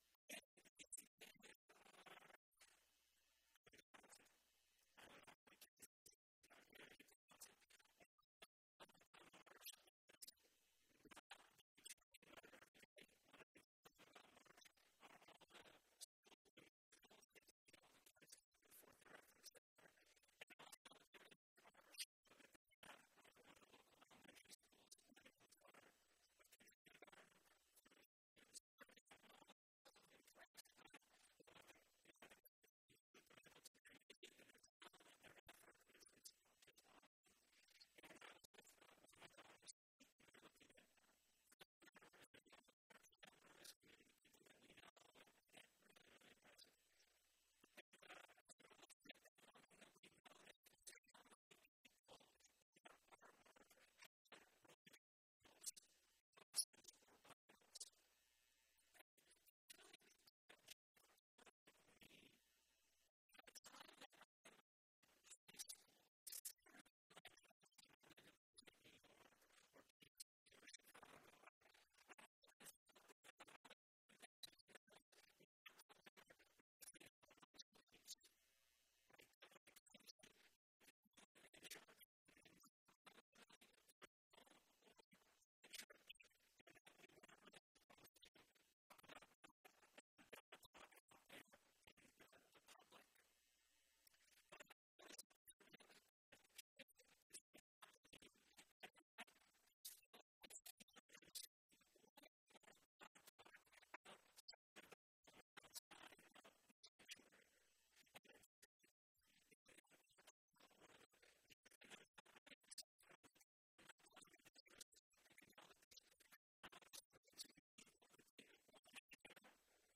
This provides a way to podcast the sermons.
Be a guest on this podcast Language: en-us Genres: Christianity , Religion & Spirituality Contact email: Get it Feed URL: Get it iTunes ID: Get it Get all podcast data Listen Now... Talking the talk and Walking the walk!